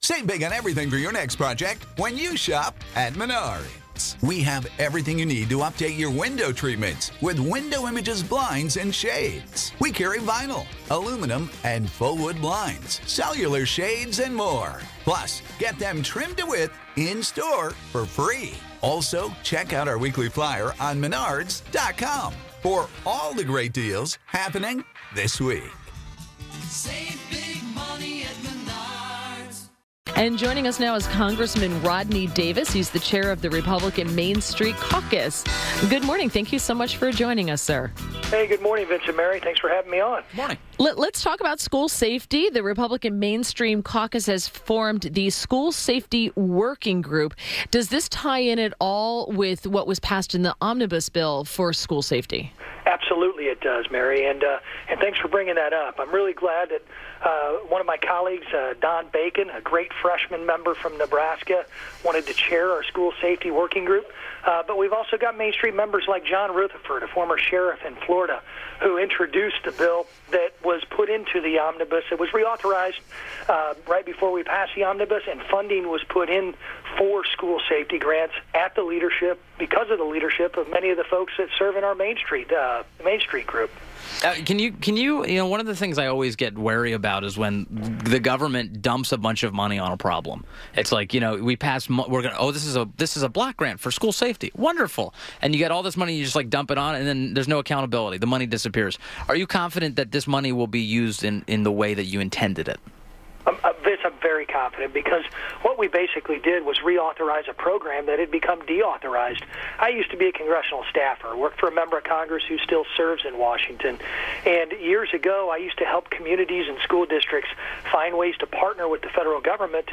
WMAL Interview - Congressman RODNEY DAVIS - 03.29.18